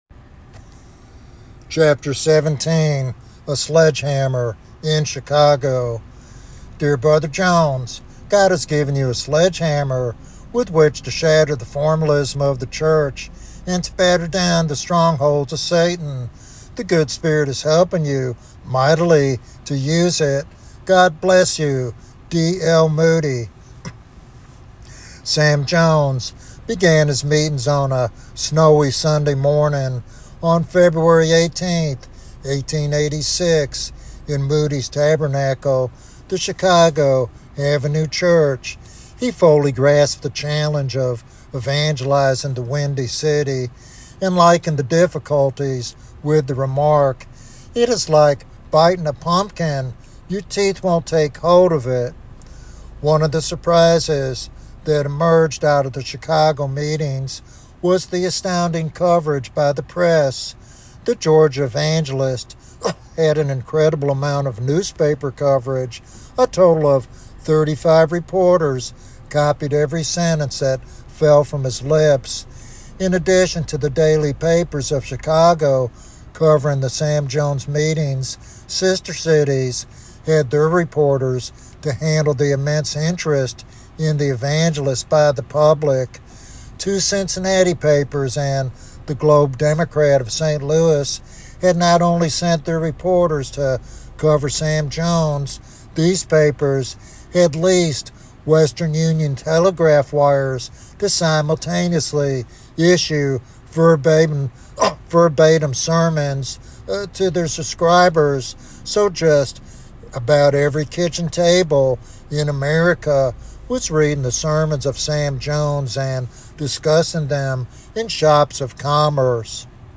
This biographical sermon reveals how one man's fervent preaching ignited a widespread awakening and transformed a major urban center.